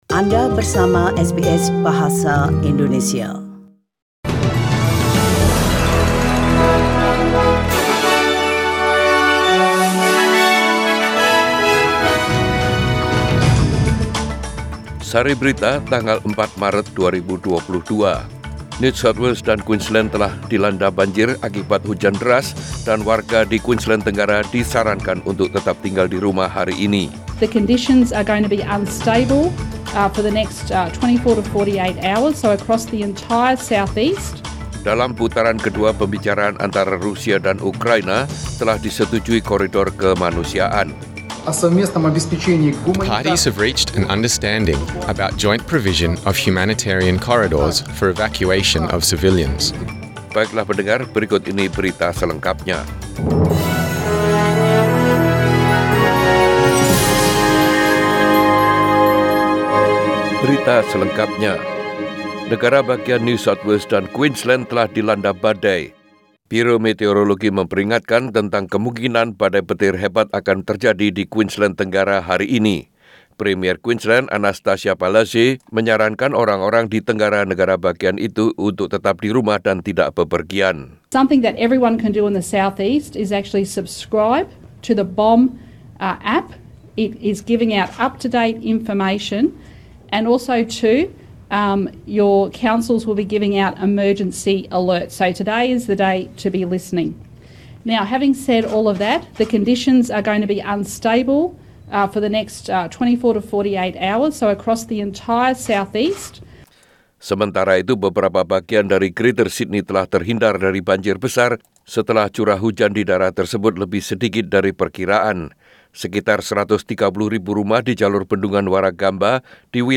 SBS Radio News in Bahasa Indonesia - 4 March 2022